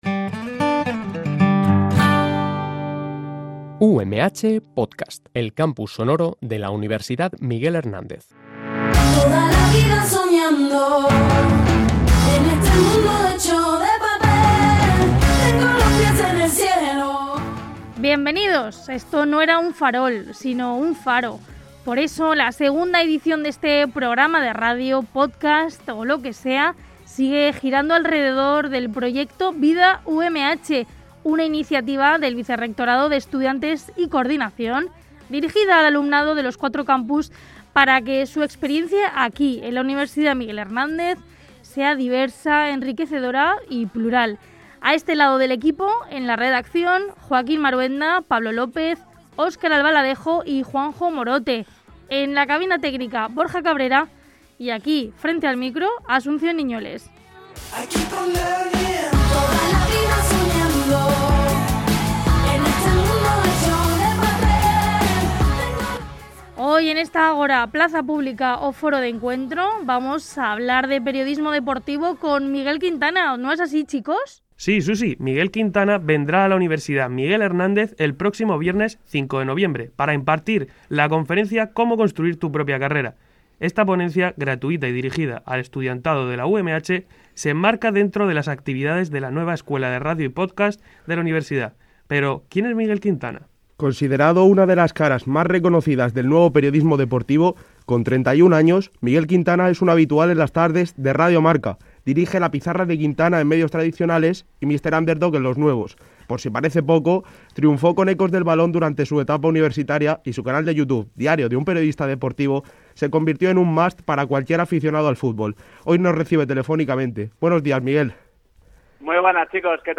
-El cómico Róber Bodegas, uno de los integrantes del dúo artístico «Pantomima Full», que actuó en el Aula Magna edificio Altabix del campus de Elche de la UMH.